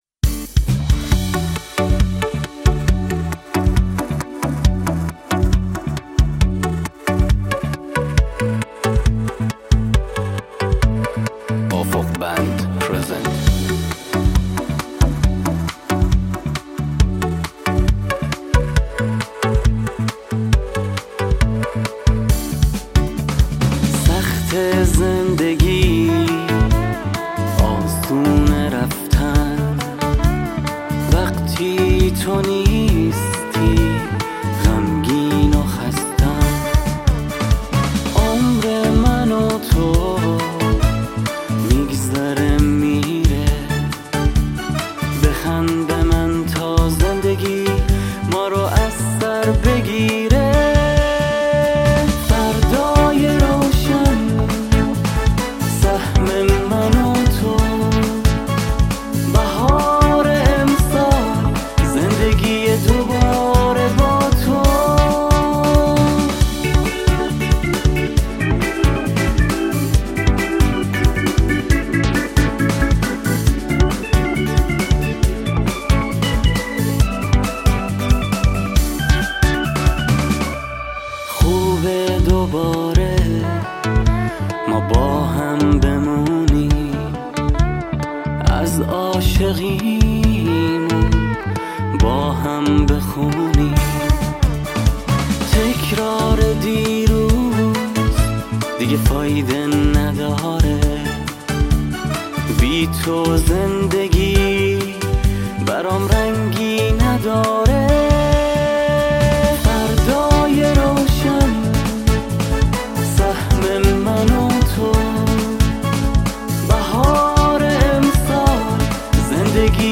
گیتار الکتریک
کیبورد
ساکسیفون